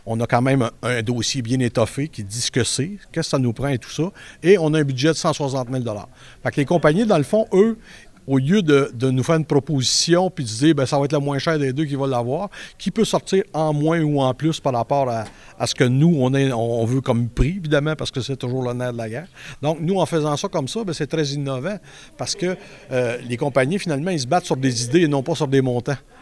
Plutôt que d’y aller par soumission au plus bas soumissionnaire, Nicolet a inversé le processus, comme l’a expliqué le conseiller municipal, Denis Jutras.